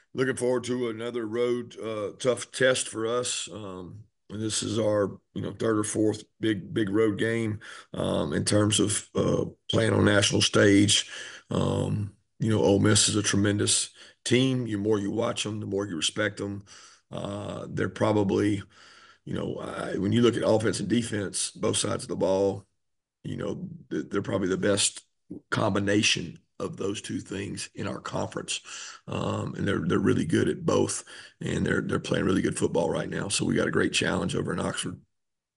Georgia coach Kirby Smart discusses his thoughts on Ole Miss: